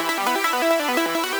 SaS_Arp05_170-E.wav